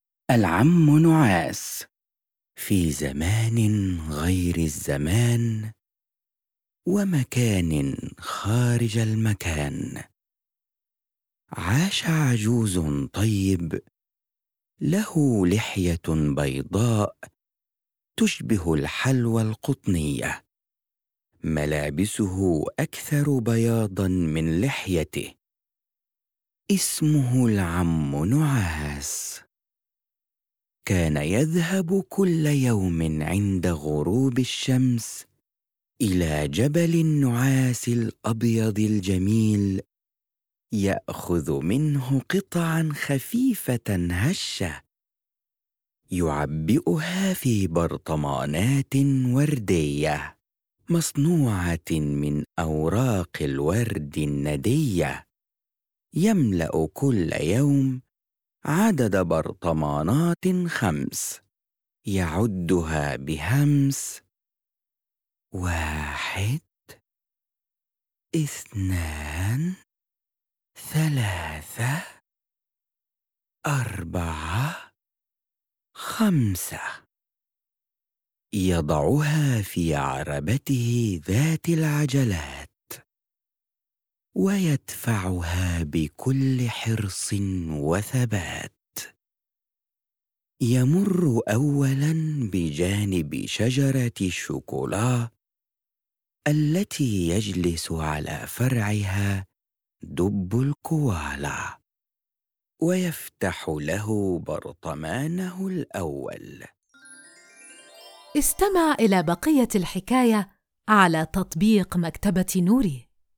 كل قصة يتم تقديمها بصوت جذاب جميل، مع مؤثرات لتحفيز الطفل على التفاعل والاندماج في عالم الحكايات.